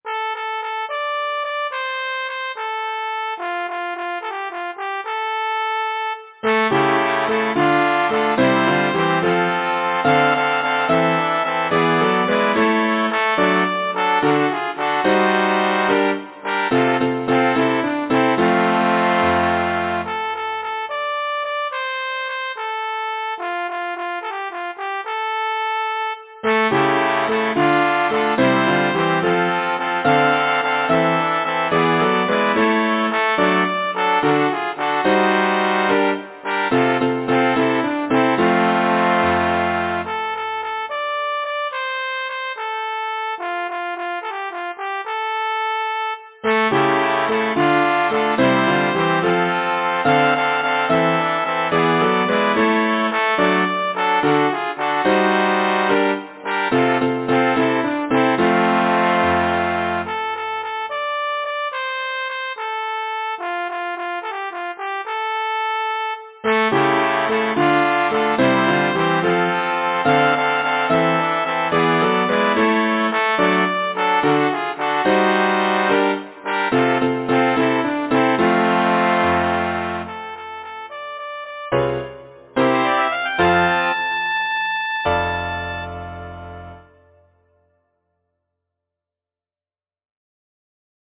Title: Why? Composer: James S. Ford Lyricist: Lyman Frank Baum Number of voices: 4vv Voicing: SATB Genre: Secular, Partsong
Language: English Instruments: Piano